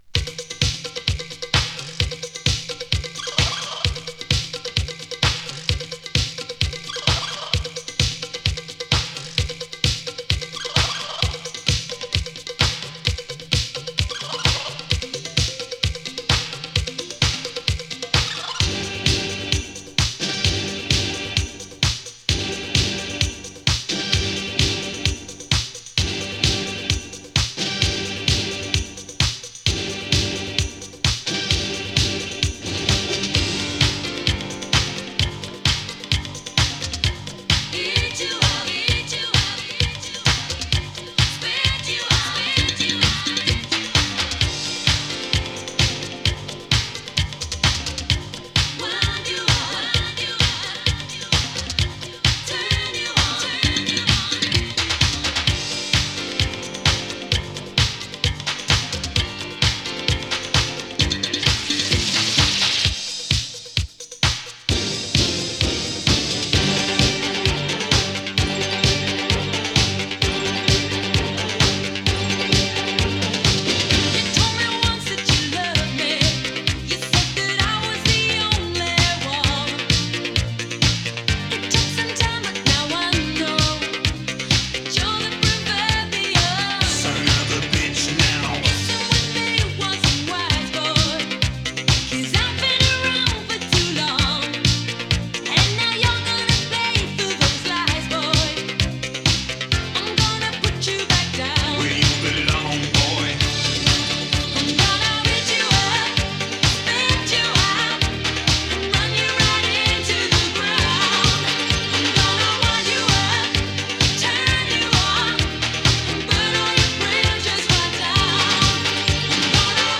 ユーロビート
イギリス出身の女性シンガー。
♪Vocal （6.10）♪